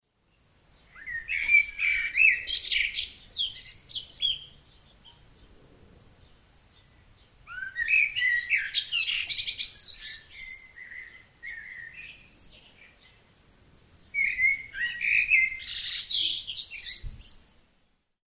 Stimme Amsel
Amsel.mp3